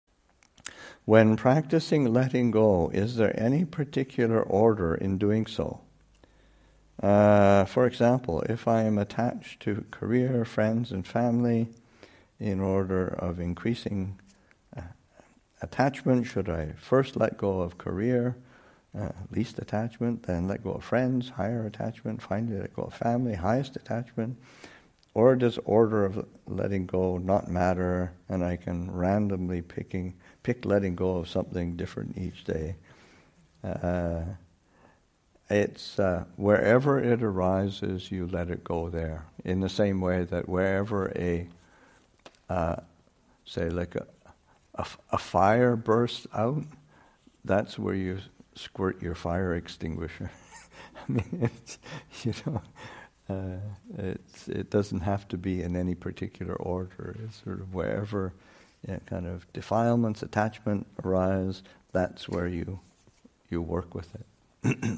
Abhayagiri 25th Anniversary Retreat, Session 19 – Jun. 14, 2021